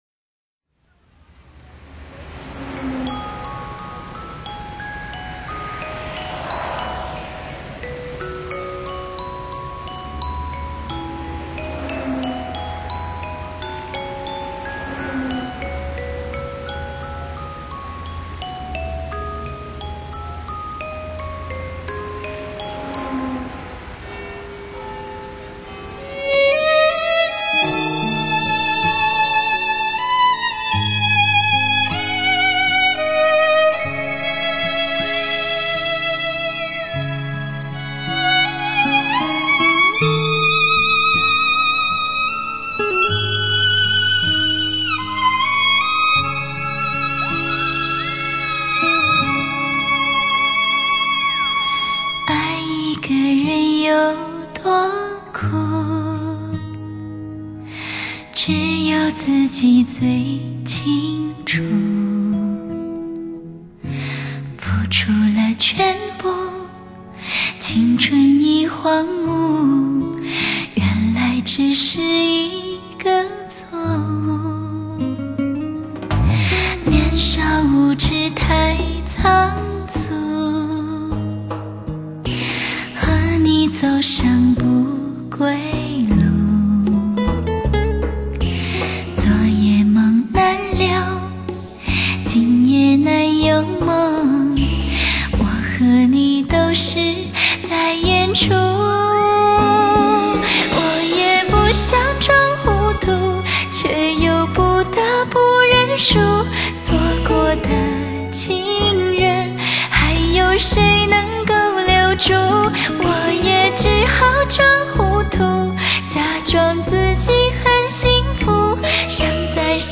伤感情怀 ◎ 暧昧游走 ◎ 绝对遐想